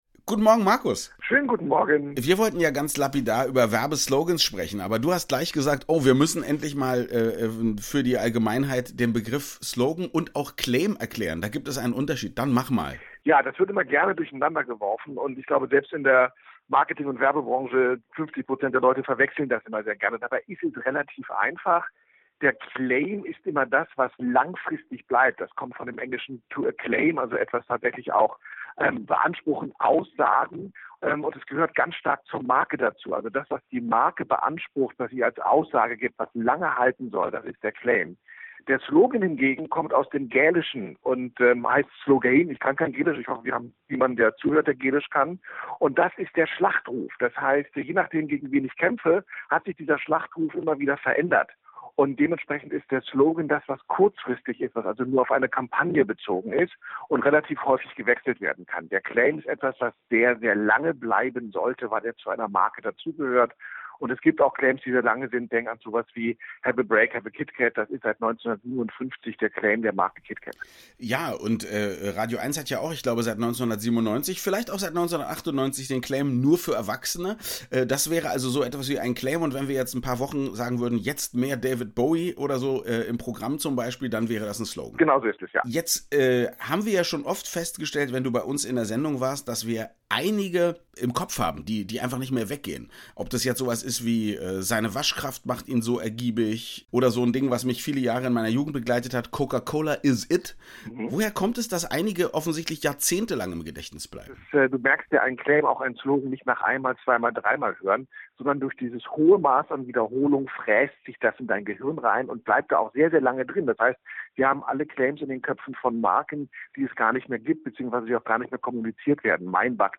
„Satz“ ist das Thema der heutigen „Zweiaufeins„-Sendung und da ist es naheliegend, sich einmal mit Werbesätzen, also Claims und Slogans zu beschäftigen. Da ich mich heute erst auf der Rückreise aus dem Urlaub befinde, kann ich nicht live im radioeins-Studio sein, das Gespräch haben wir deshalb vorher aufgezeichnet: